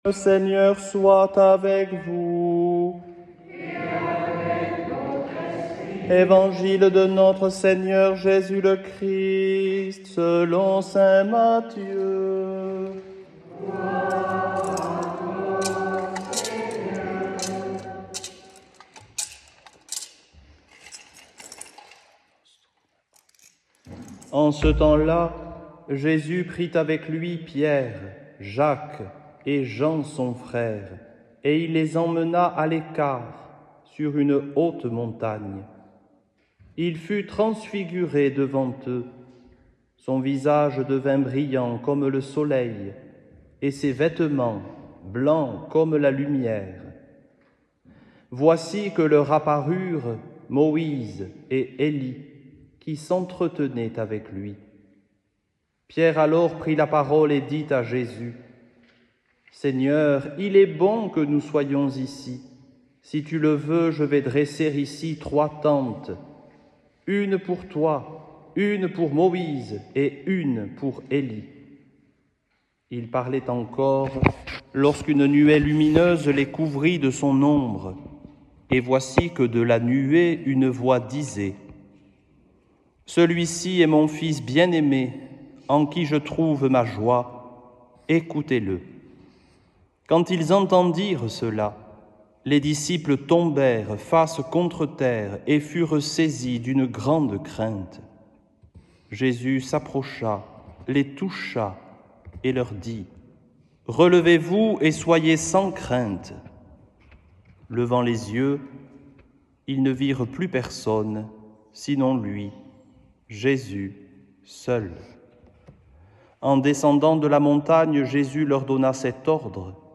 evangile-careme-2-2026-transfiguration.mp3